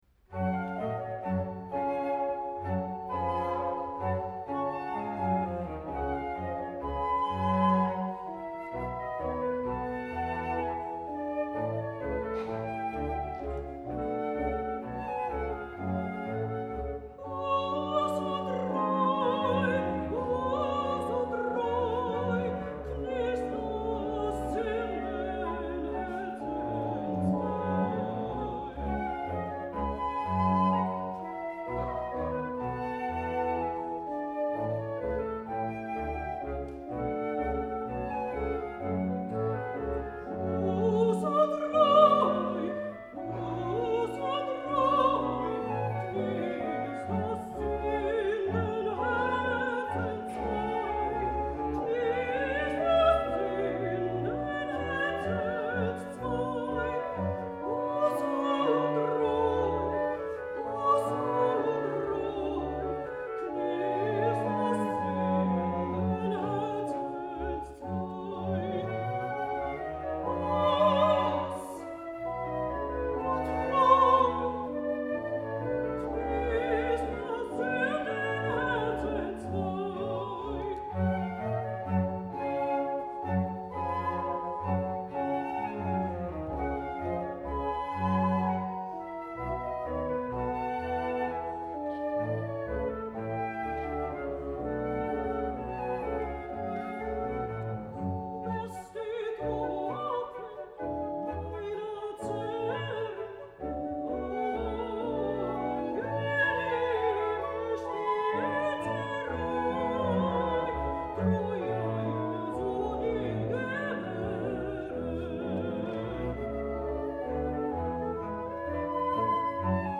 横浜マタイ研究会合唱団
２０１６年４月３０日　神奈川県立音楽堂　　曲目　マタイ受難曲 第1部 1-29曲　J.S.Bach